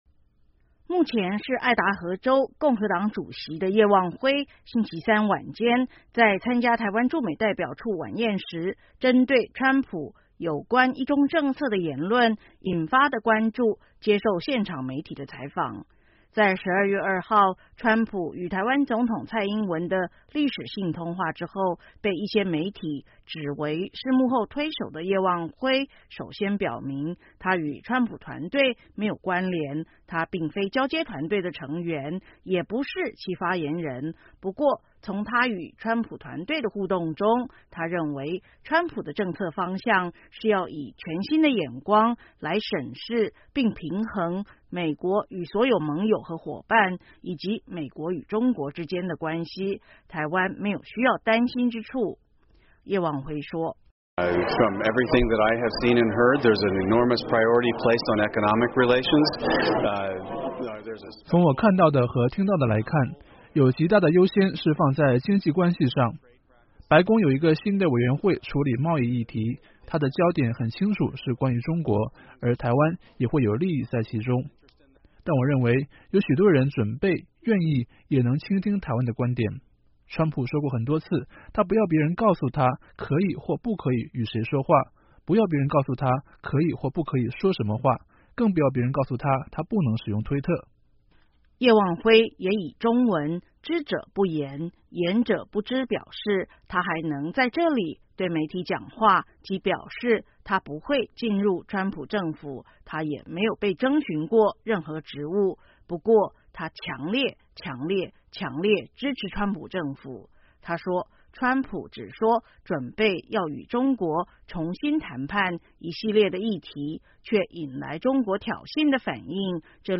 目前是爱达荷州共和党主席的叶望辉(Stephen Yates)，星期三晚间在参加台湾驻美代表处晚宴时，针对川普有关一中政策的言论引发的关注接受现场媒体的采访。